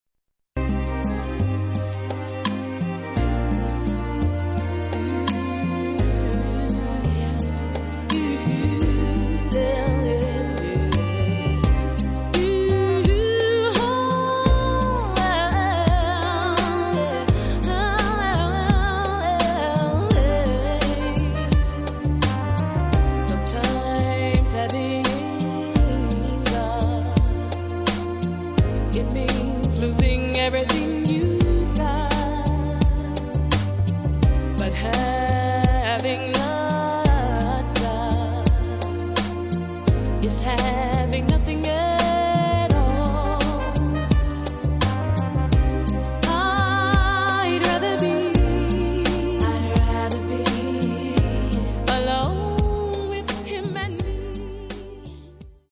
dance/electronic
RnB